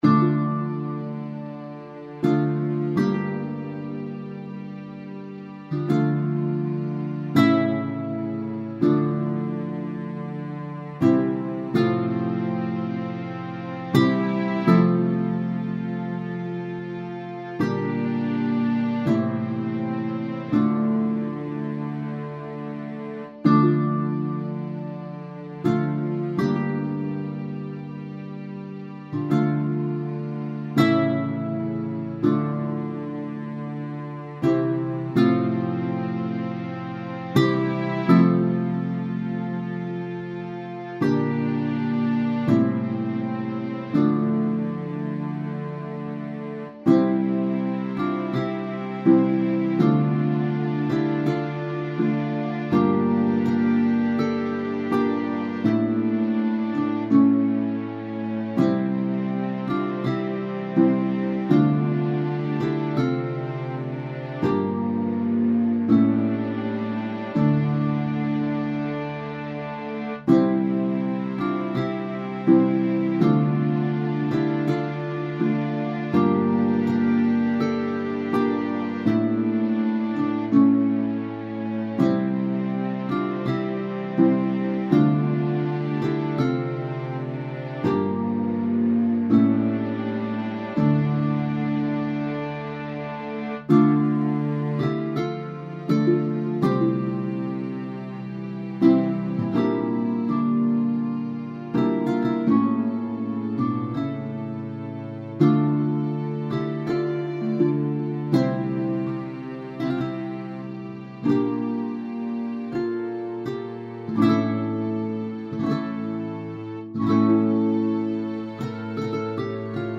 Largo [0-10] - - guitare - harpe - aerien - folk - melodieux
guitare - harpe - aerien - folk - melodieux